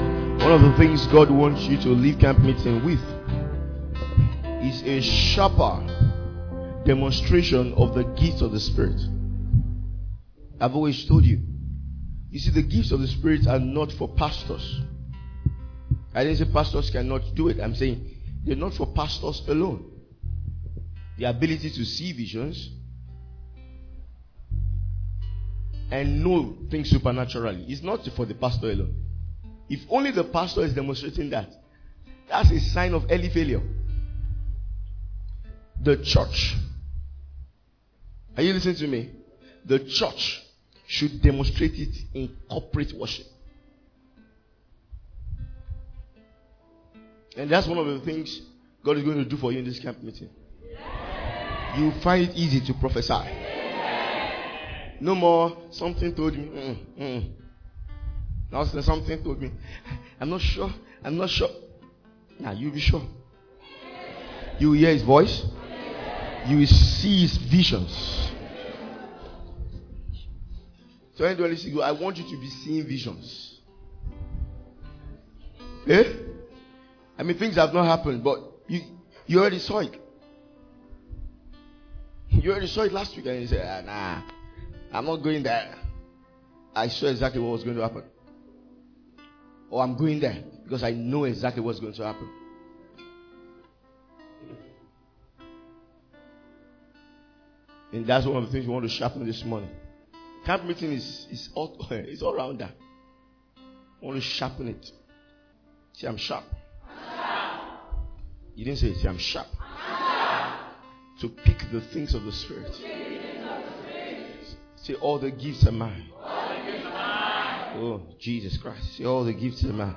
Message from our annaul Ministers’ Retreat 2025